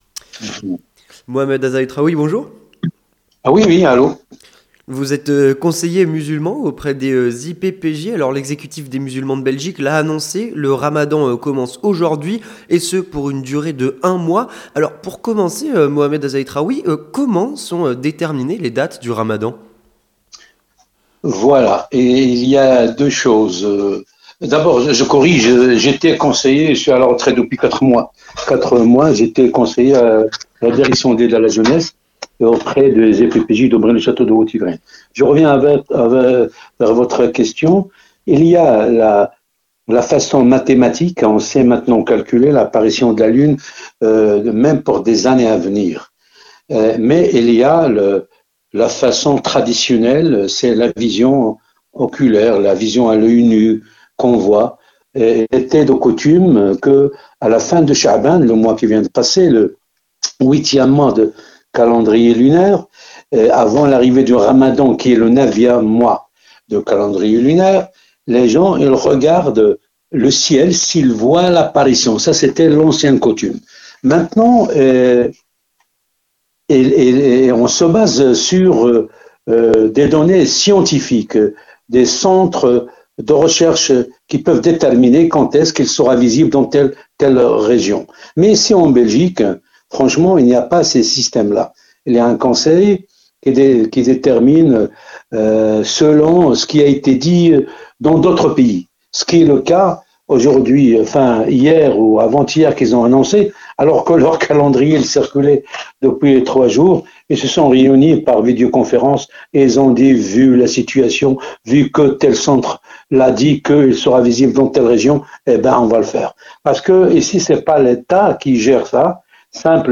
L'Entretien du 18h - Le début du Ramadan